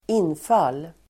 Ladda ner uttalet
infall.mp3